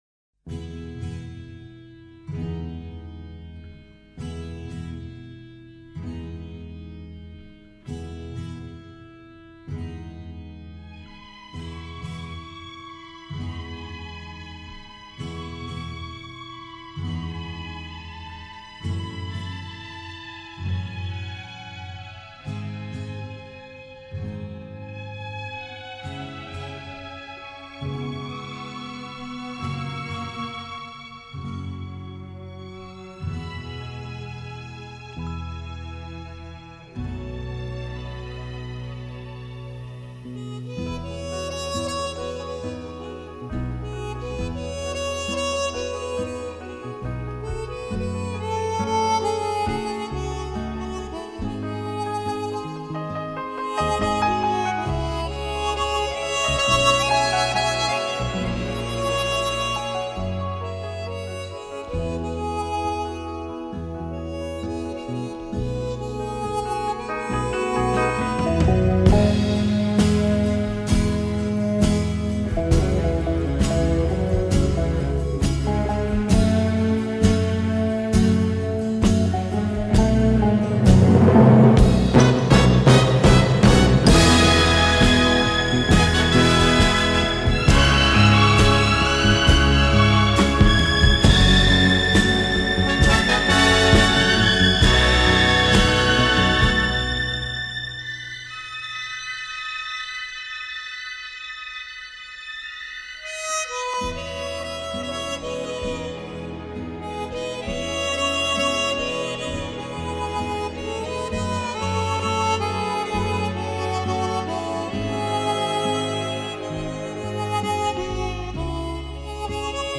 Genre:Instrumental/Easy Listening